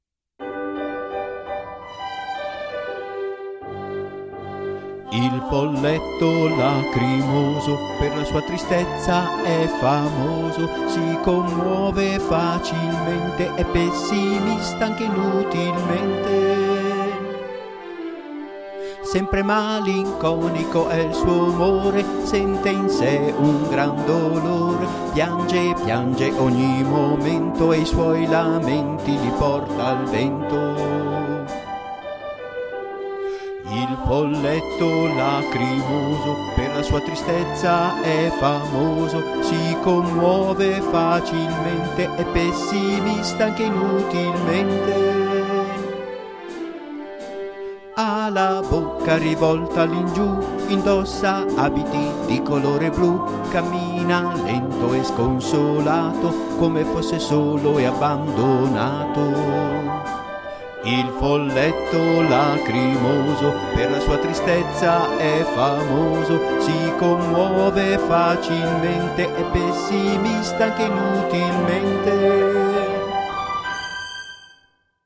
Canzoni dedicate ai sei Folletti scritte musicate e cantate